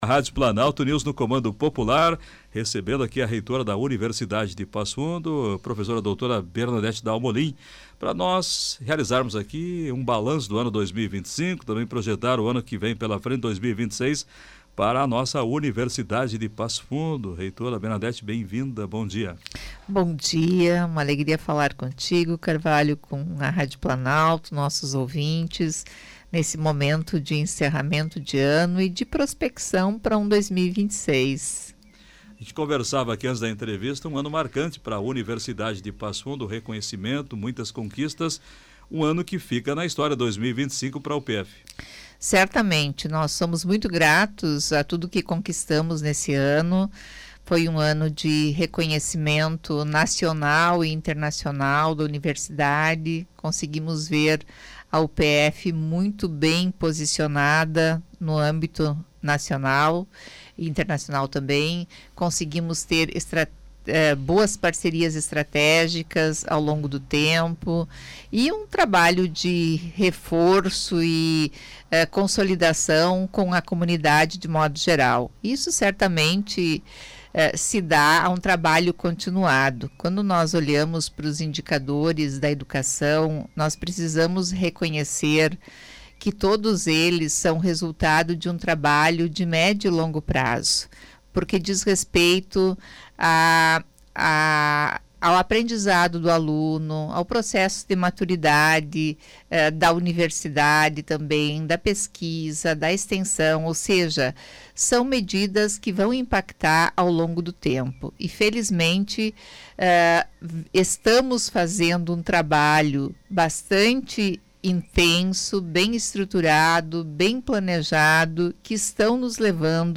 Entrevista: UPF comemora as conquistas de 2025 e se prepara para novas realizações